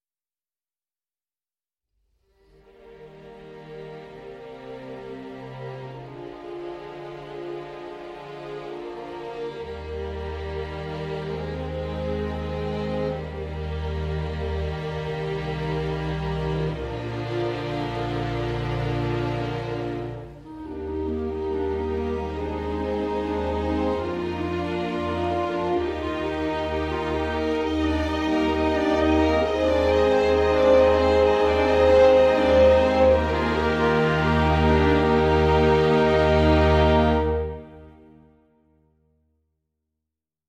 Un petit ensemble orchestral délivre une partition